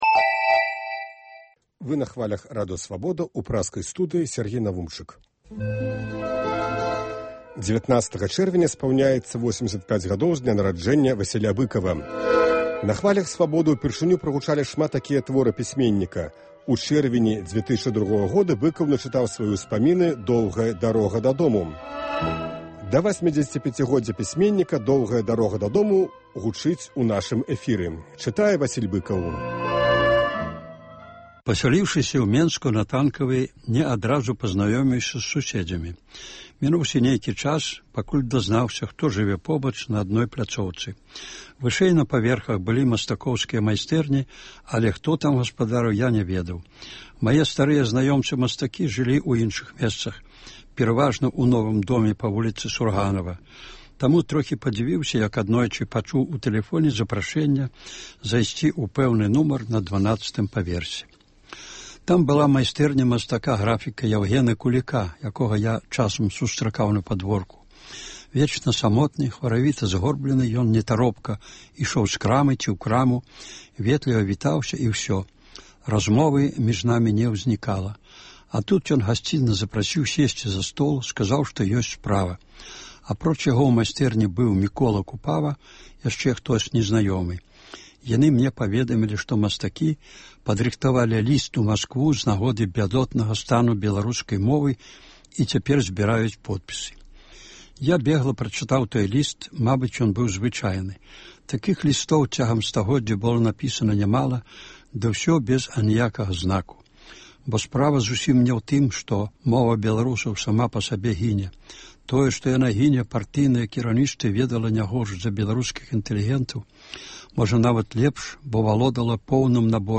Сёлета ў чэрвені штодня ў нашым эфіры гучыць “Доўгая дарога дадому” ў аўтарскім чытаньні. Сёньня – частка 12-ая.